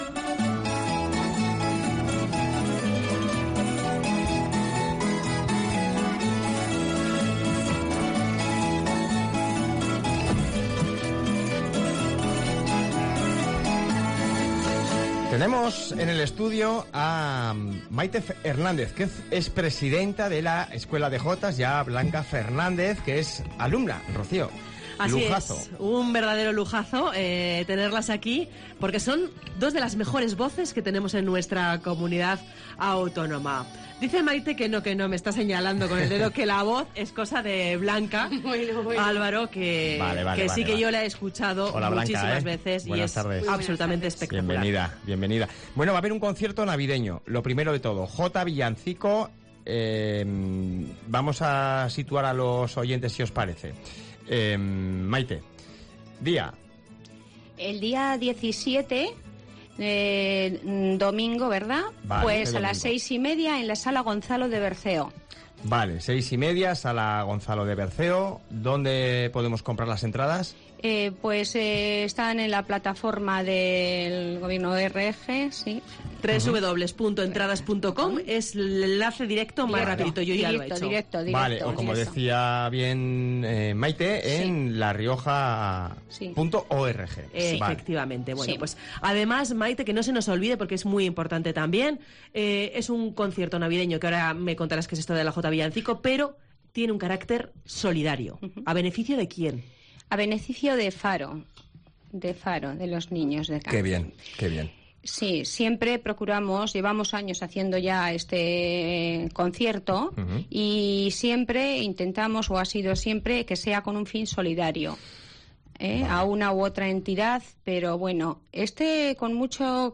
Jotas villancico de la Escuela de Jotas ponen el sonido a la Navidad de La Rioja: "El Ebro en Belén"